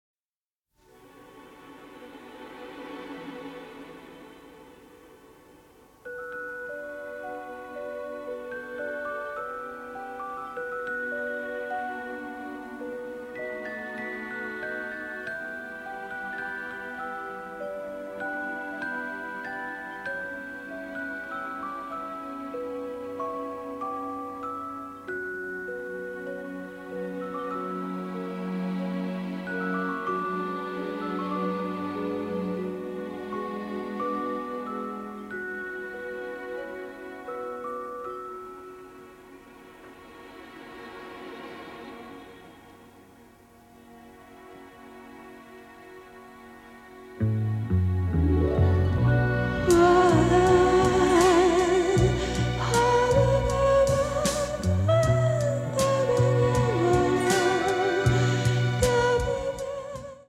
a wonderful romantic horror score
in beautiful pristine stereo